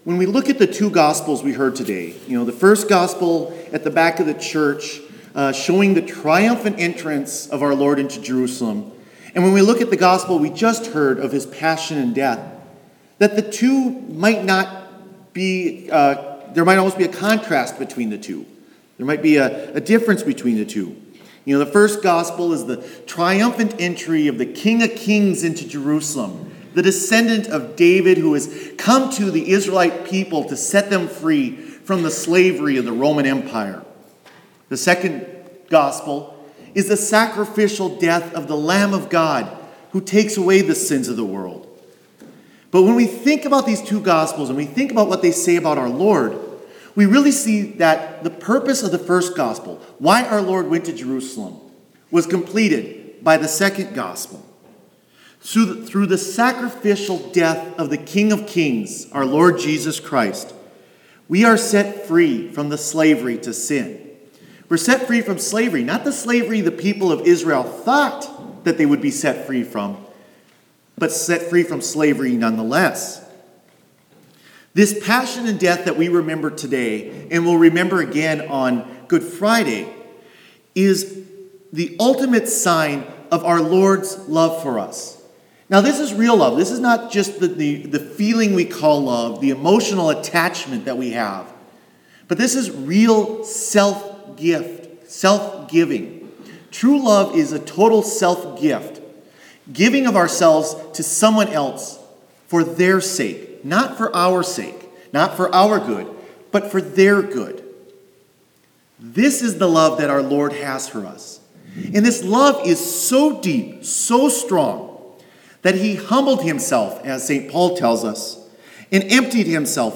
Homily for Palm Sunday